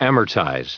added pronounciation and merriam webster audio
2001_amortize.ogg